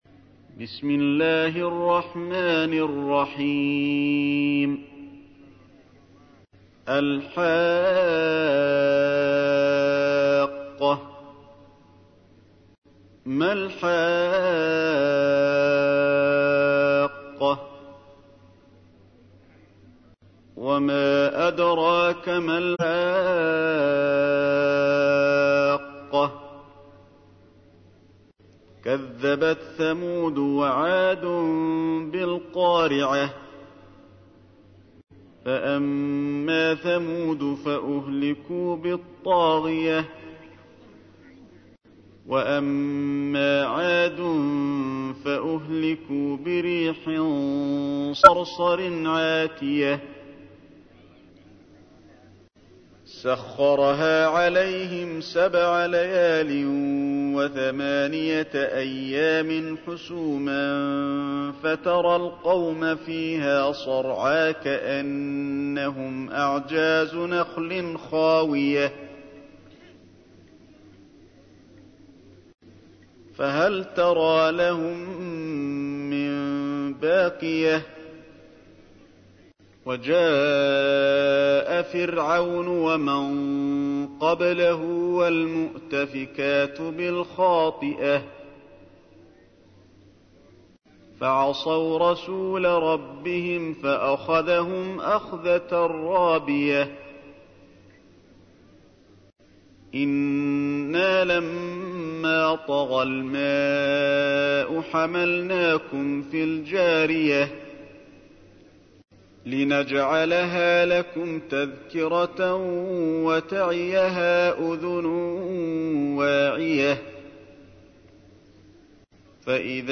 تحميل : 69. سورة الحاقة / القارئ علي الحذيفي / القرآن الكريم / موقع يا حسين